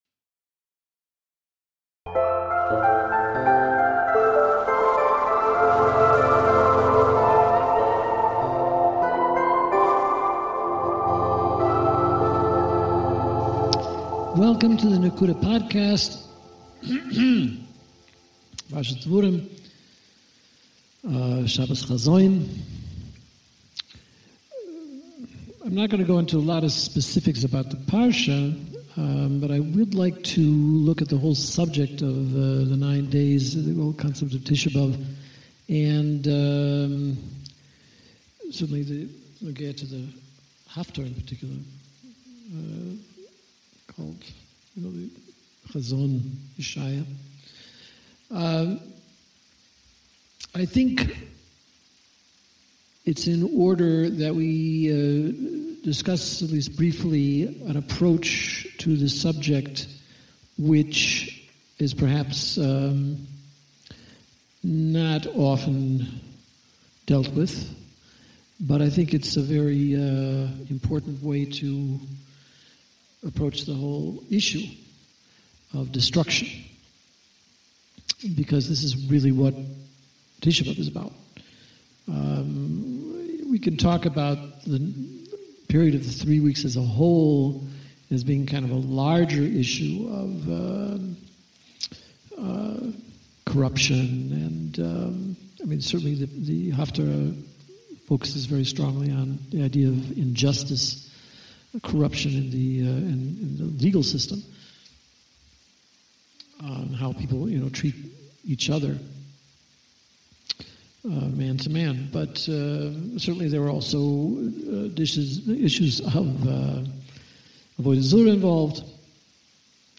Instead of the Weekly Parsha, we will begin learning the classic sefer Nesivos Olam by the Maharal of Prague. This shiur is being given in the famous Alshich Shul in Tzfas, and we hope it will be an ongoing feature. The introduction deals with the importance of determining, and sticking to, a clear, straight path in life.